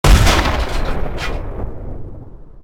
Tank_Turret3.ogg